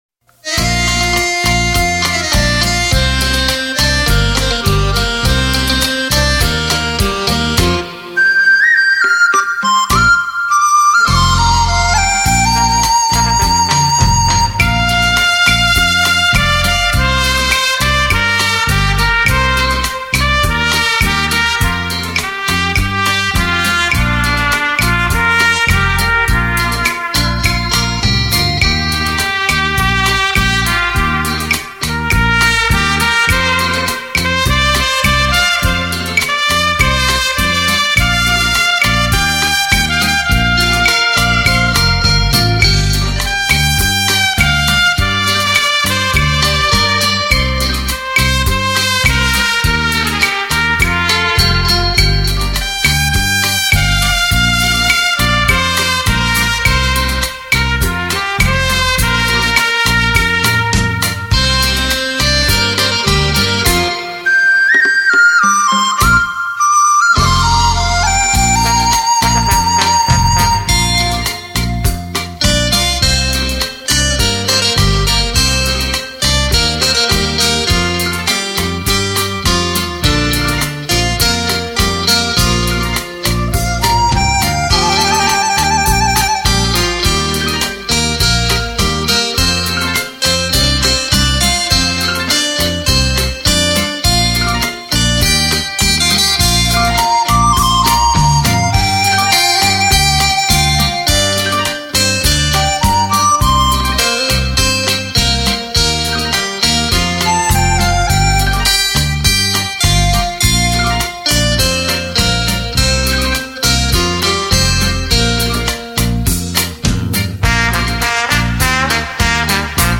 属性：音乐、舞曲CD、标准型光盘、散曲合辑
专辑格式：DTS-CD-5.1声道
按照舞厅的标准现场，标准速度来演奏的舞曲系列之一。
30人大乐团环场演奏，是舞林朋友的华丽舞伴。